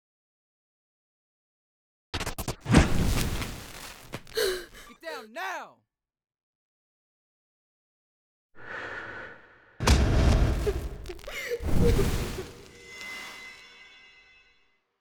FA103_AllNew_Next15_SFX.wav